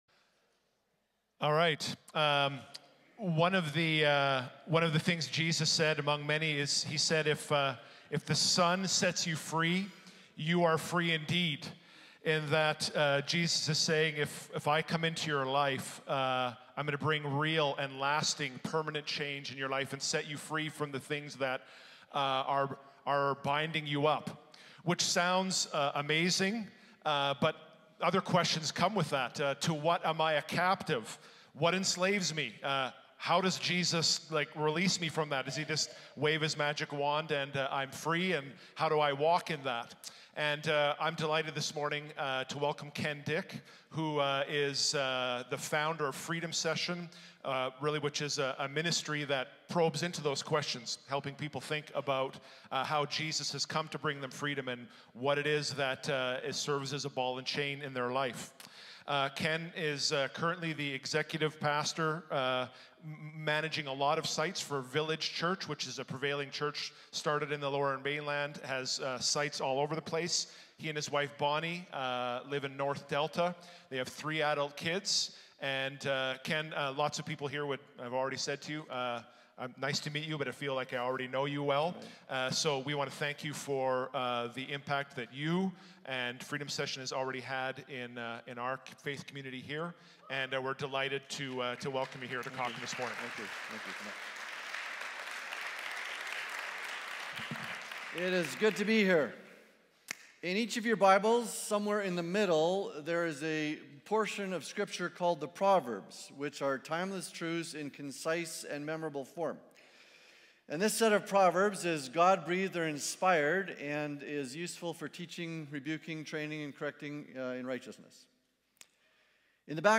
Cochrane Alliance Church Sermons | Cochrane Alliance Church